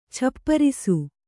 ♪ chapparisu